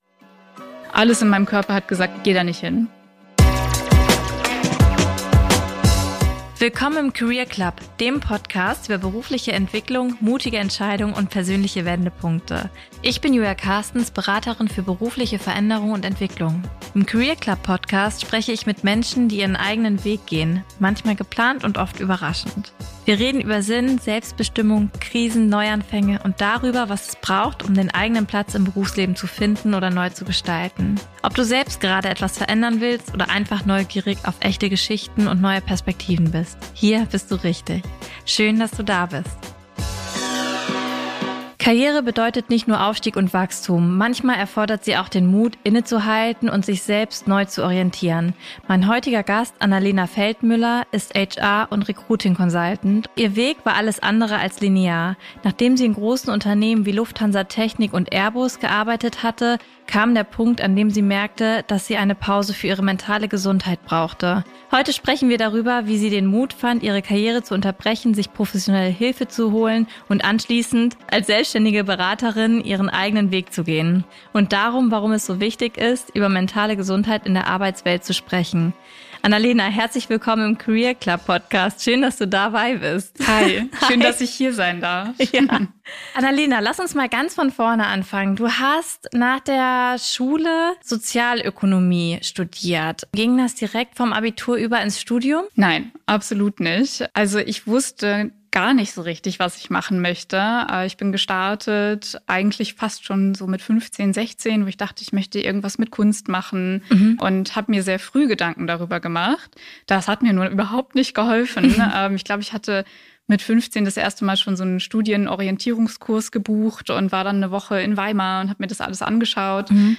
Ein Gespräch über mentale Gesundheit in der Arbeitswelt, über Mut zur Veränderung und die Kraft, sich selbst nicht aufzugeben.